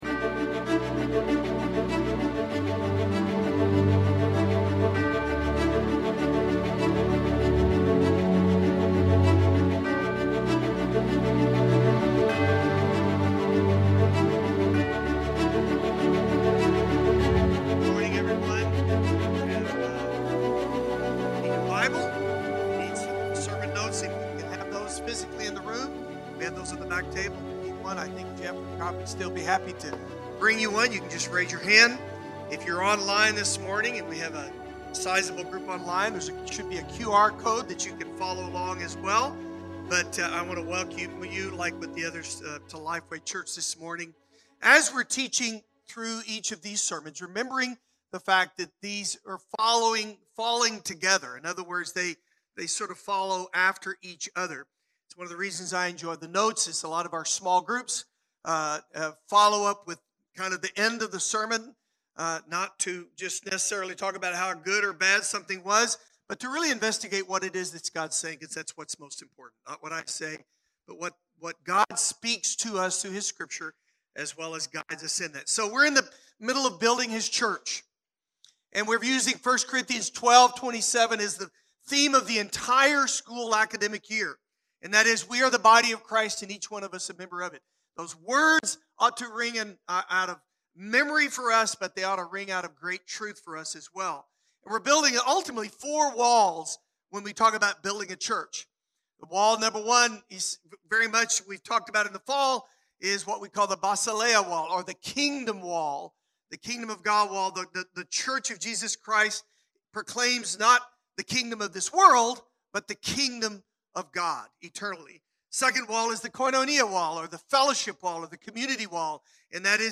Download the sermon notes (adult version)